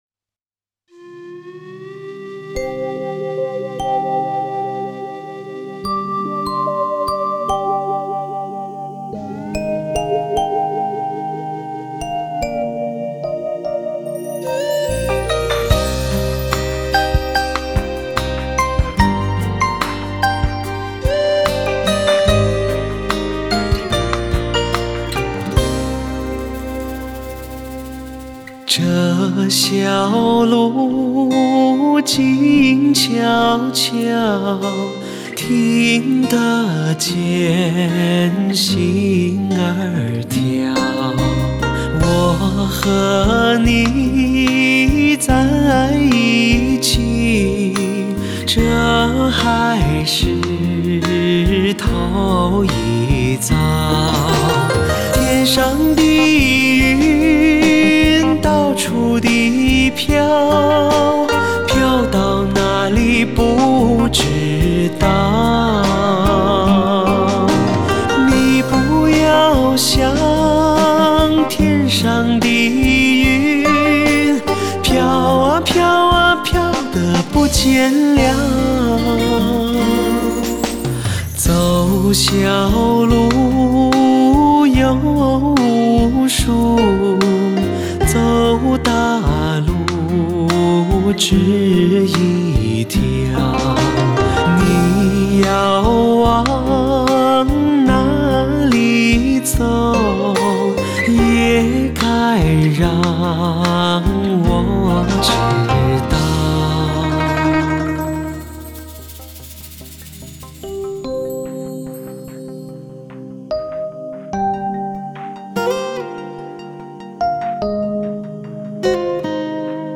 国语流行